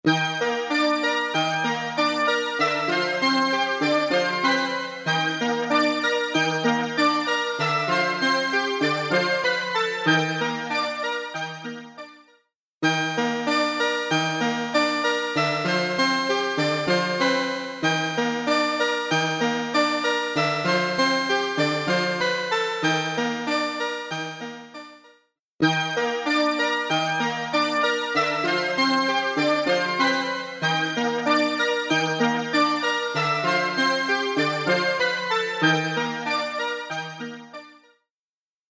TriceraChorusは、サウンドに極上の豊かさと空間的な奥行きをもたらす、洗練されたコーラス・ソリューションです。
TriceraChorus | Poly Synth | Preset: Slow & Steady
TriceraChorus-Eventide-Poly-Synth-Slow-And-Steady.mp3